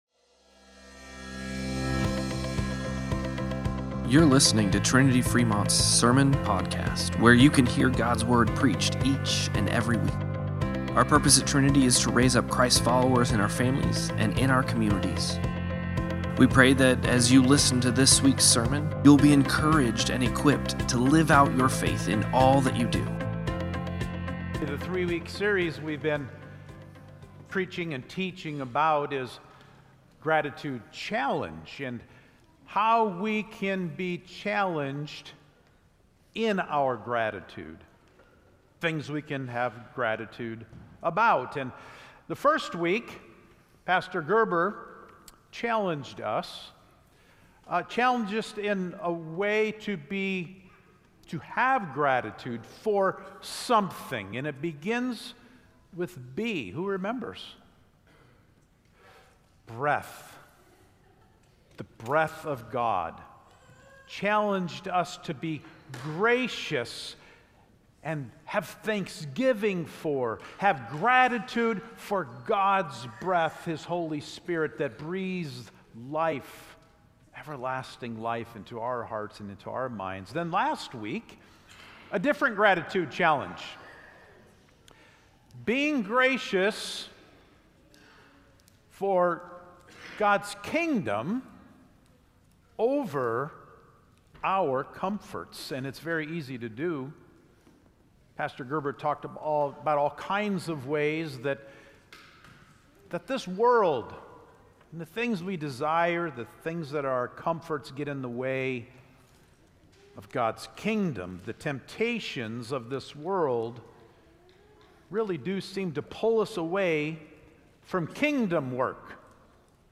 Sermon-Podcast-11-23.mp3